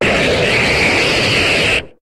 Cri de Kravarech dans Pokémon HOME.